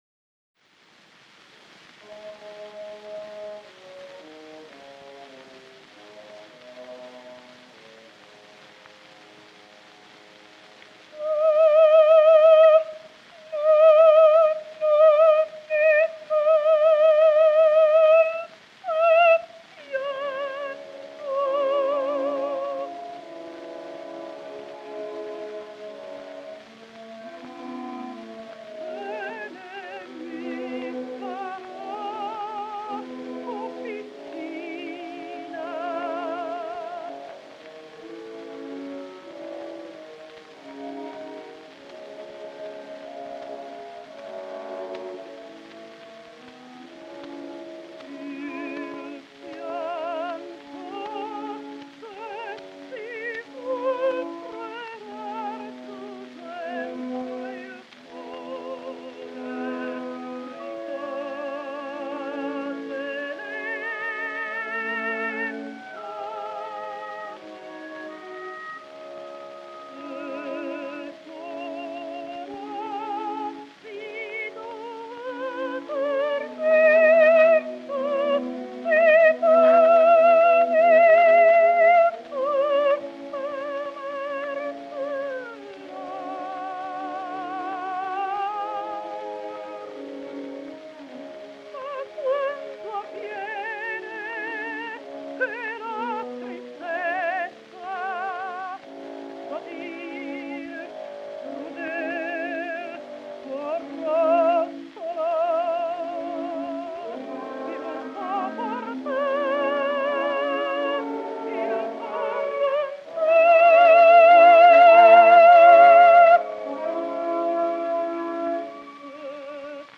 A l’ària de les llàgrimes, la línia melòdica és ondulant i descendent per mostrar el plor de la protagonista.